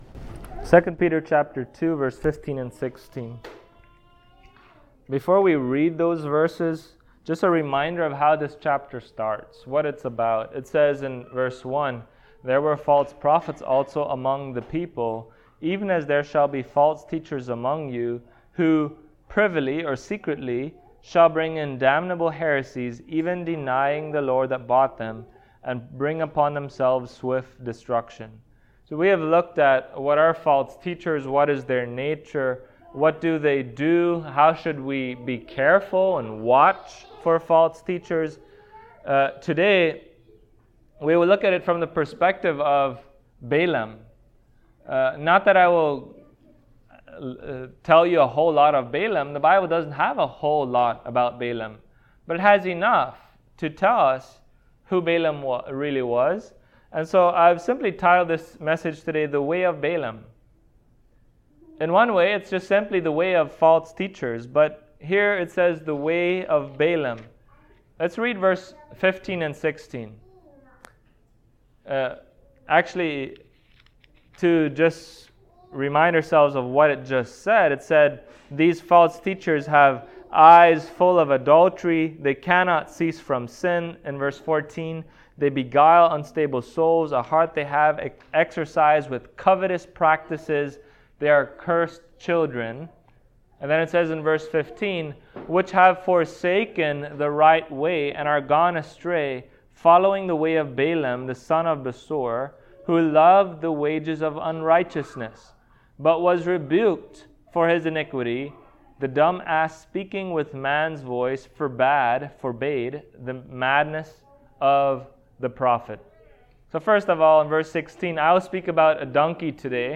Service Type: Sunday Morning Topics: False Prophets